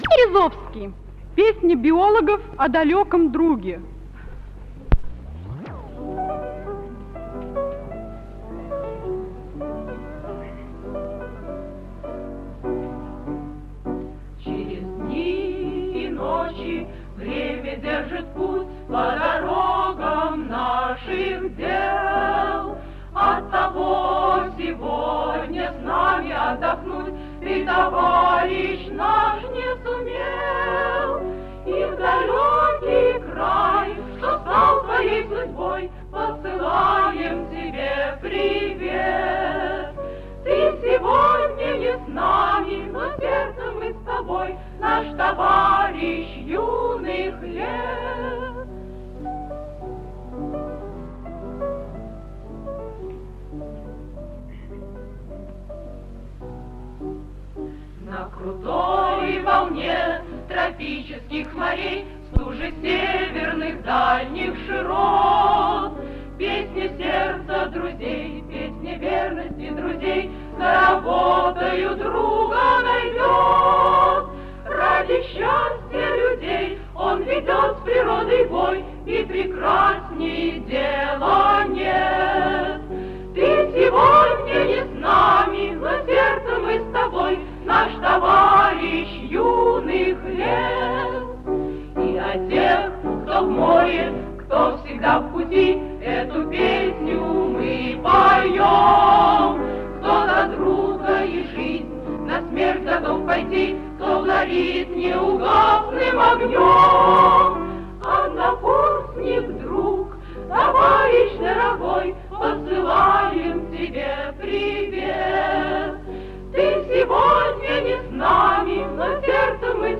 ВТОРОЙ МОСКОВСКИЙ ВЕЧЕР-КОНКУРС СТУДЕНЧЕСКОЙ ПЕСНИ
Женский ансамбль Биофака МГУ